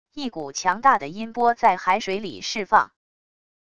一股强大的音波在海水里释放wav音频